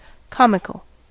comical: Wikimedia Commons US English Pronunciations
En-us-comical.WAV